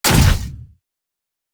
mixkit-falling-hit-757.wav